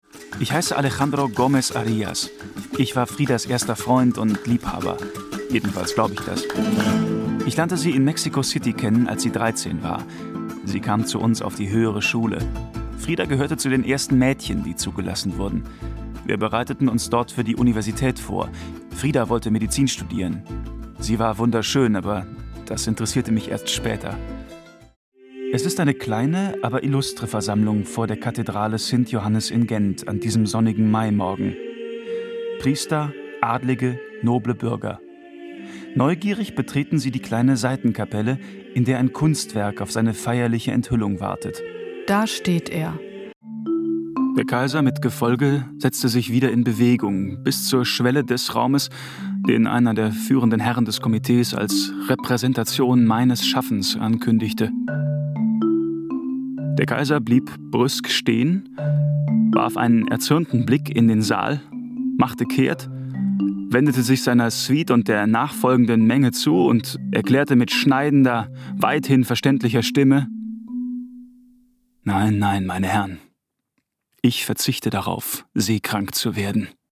Sprecher deutsch. Frische, klare, direkte Stimme.
Sprechprobe: Werbung (Muttersprache):
voice over artist german